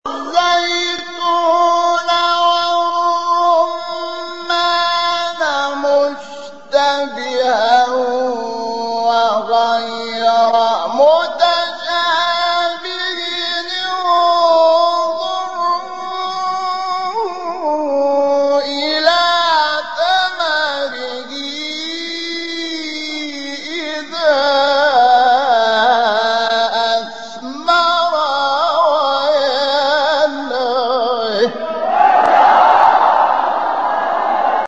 سایت قرآن کلام نورانی - سه گاه انور شحات جواب جواب (1).mp3